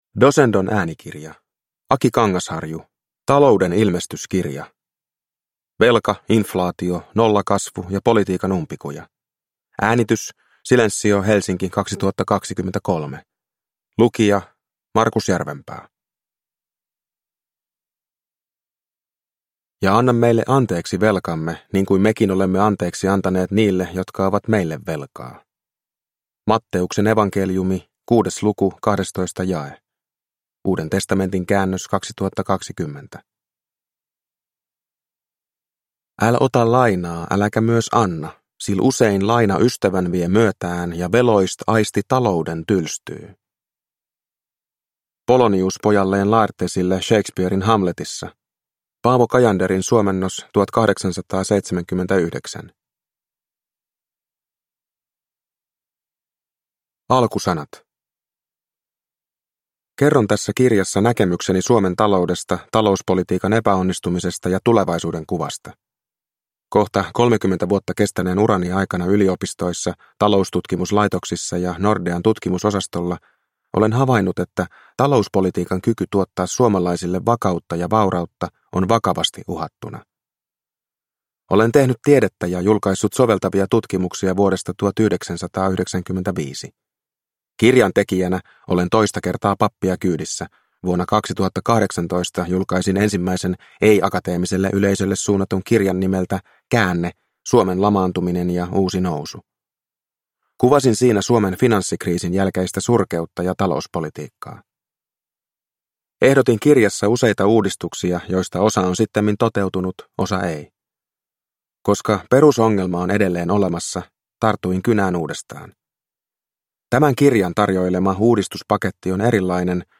Talouden ilmestyskirja – Ljudbok – Laddas ner